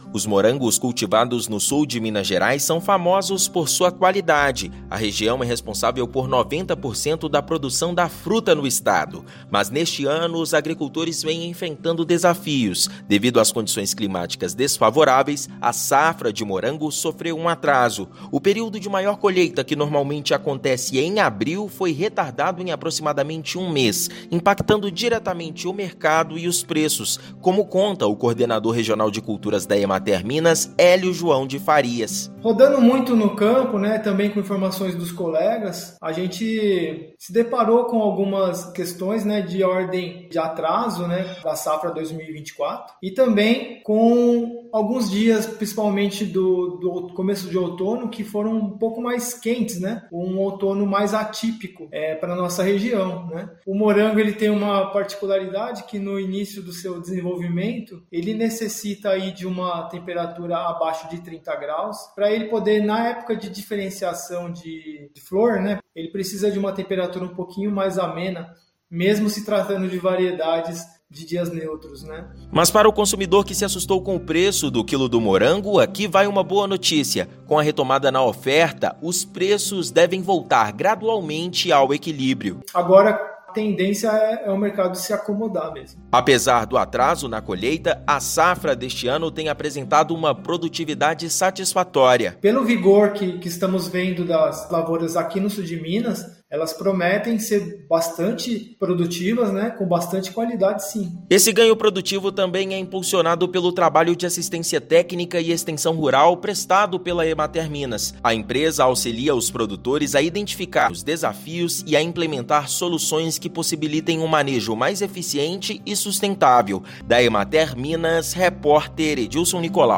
Produtores do Sul de Minas abastecem mercado; maior oferta deve reduzir preços para o consumidor final. Ouça matéria de rádio.